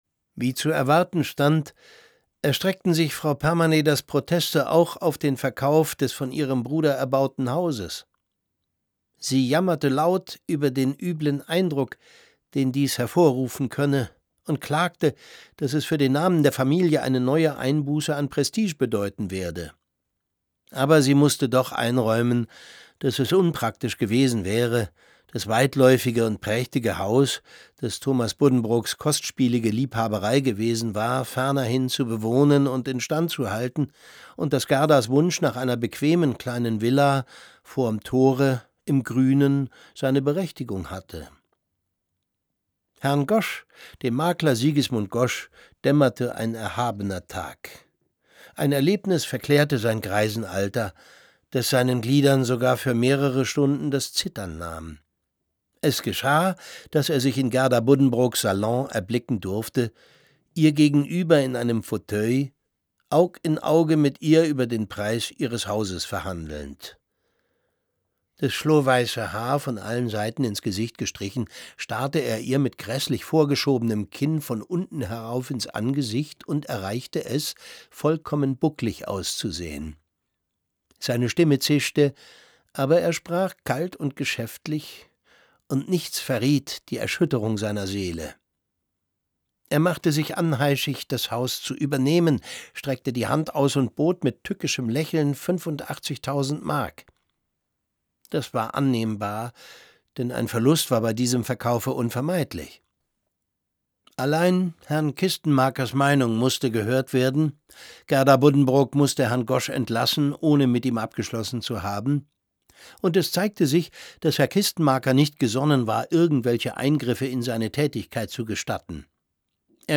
Es liest Thomas Sarbacher.